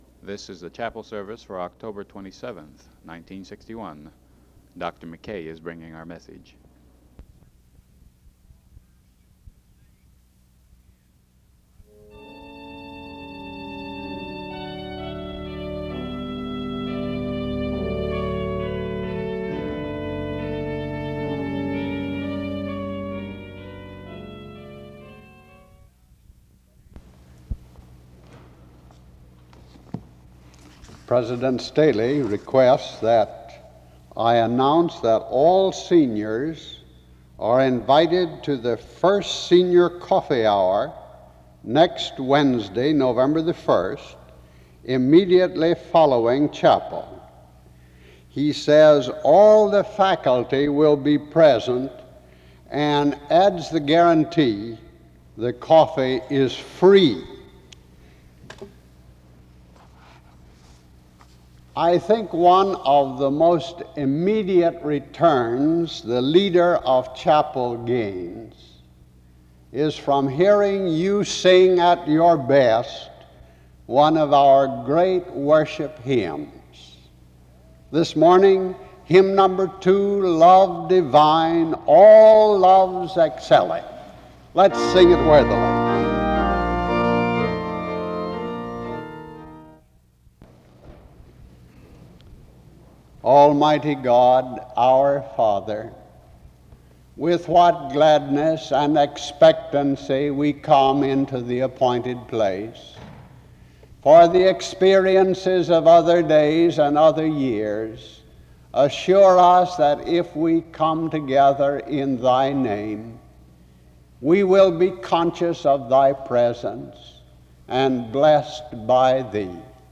SEBTS Chapel
There is an opening prayer and a scripture reading from Luke 15:11-31.
This hymn plays from 10:07-15:35.
The Prayer of Saint Francis is sung from 20:45-23:03.